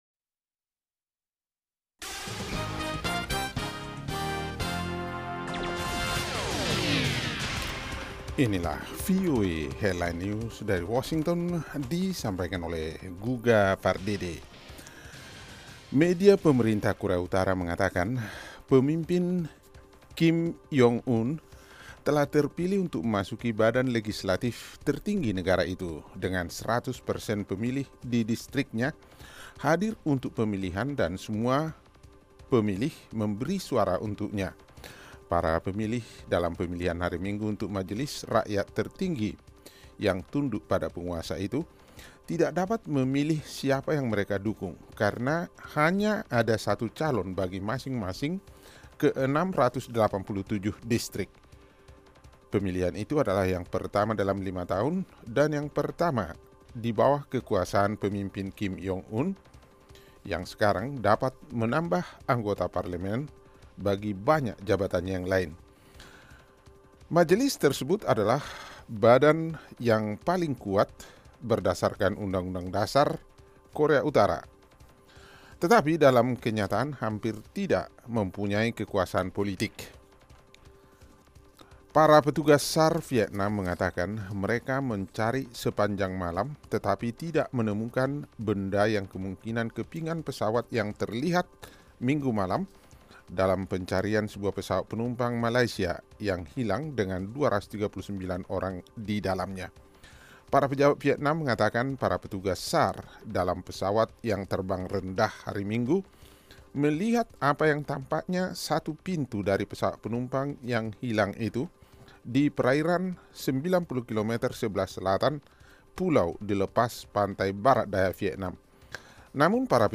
Simak berita terkini setiap 30 menit langsung dari Washington dalam Headline News, bersama para penyiar VOA yang setia menghadirkan perkembangan terakhir berita-berita internasional.